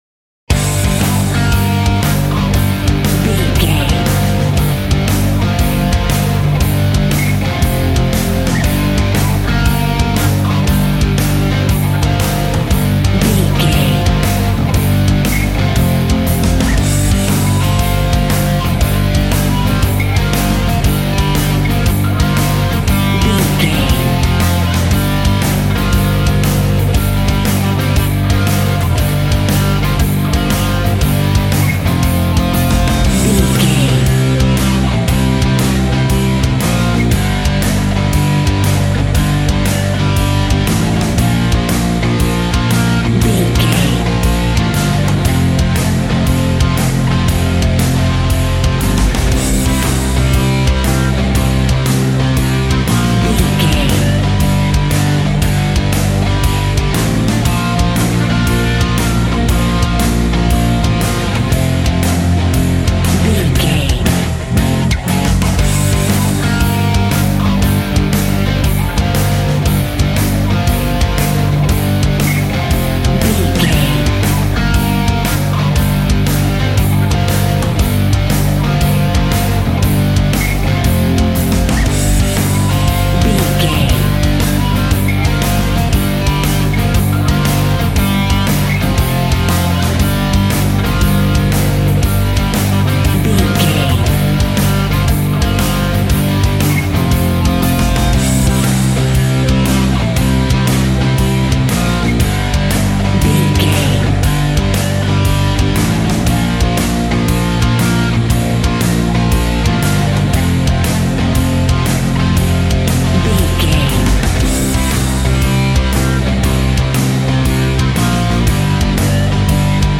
Aeolian/Minor
aggressive
electric guitar
bass guitar